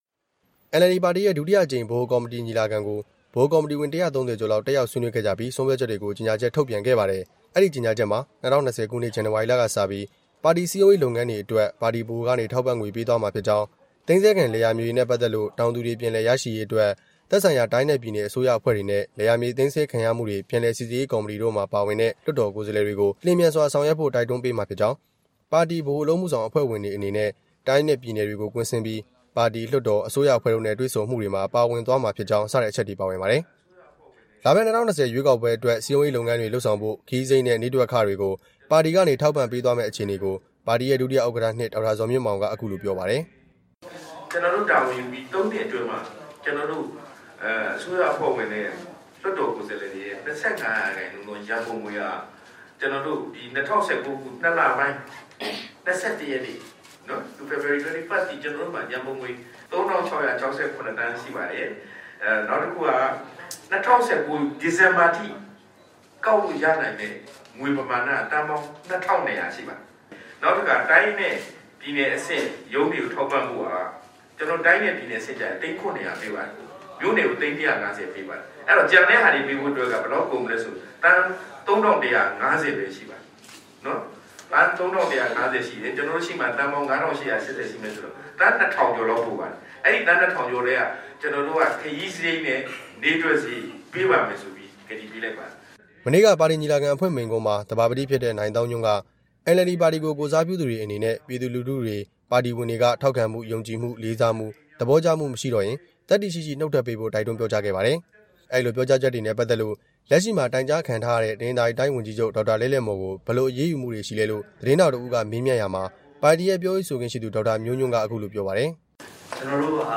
ရန်ကုန်မြို့မှာ နှစ်ရက်ကြာကျင်းပခဲ့တဲ့ NLD ပါတီ ဒုတိယအကြိမ် ပါတီညီလာခံအပြီး ကျင်းပတဲ့ သတင်းစာရှင်းလင်း ပွဲမှာ တပ်မတော်က လွှတ်တော်တွင်းမှာ NLD ပါတီရဲ့ ဖွဲ့စည်းပုံပြင်ဆင်ရေး ဆောင်ရွက်မှုအပေါ် ကန့်ကွက်နေ တာနဲ့ပတ်သက်လို့ သတင်းထောက်တစ်ဦးက မေးမြန်းရာမှာ ဒေါက်တာ ဇော်မြင့်မောင်က မေးခွန်းထုတ်လိုက် တာဖြစ်ပါတယ်။ ဒါနဲ့ပတ်သက်လို့ တပ်မတော်သား လွှတ်တော် ကိုယ်စားလှယ် အစုအဖွဲ့ခေါင်းဆောင် ဗိုလ်မှူးချုပ် မောင်မောင် ကို  RFA က ဆက်သွယ်ပေမယ့် ဆက်သွယ်လို့ မရခဲ့ပါဘူး။